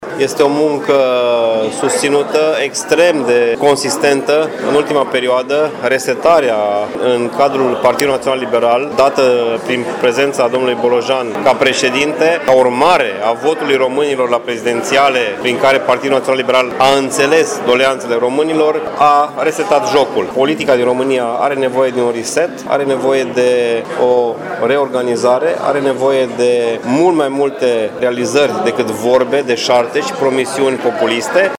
Liberalii din Timiș au așteptat anunțarea primelor rezultate exit-poll la sediul din Piața Unirii.